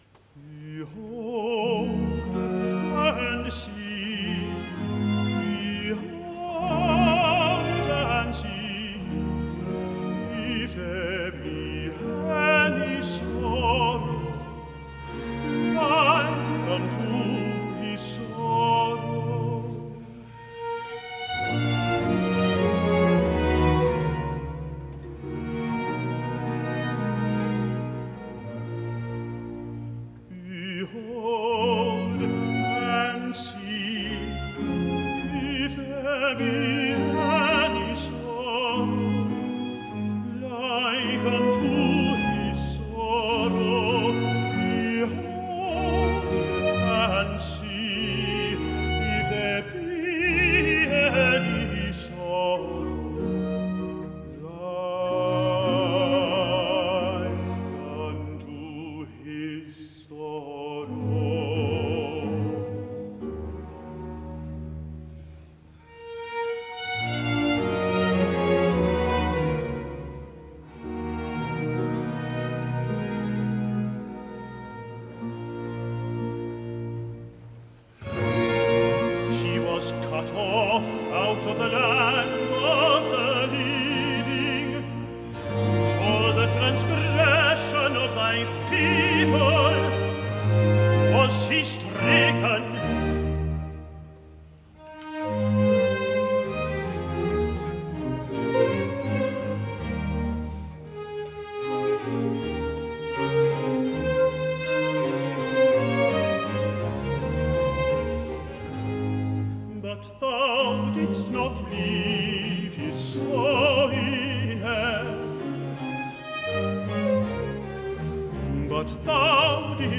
Del reconegut tenor John Mark Ainsley, us deixo l’ària “Behold, and see if there be any sorrow” seguit del recitatiu i ària “But thou didst not leave his soul” que com ja us he comentat, acostuma a cantar la soprano.
MUSIKFEST ERZGEBIRGE – Eröffnungskonzert
John Mark Ainsley, Tenor
Sankt Marienkirche Marienberg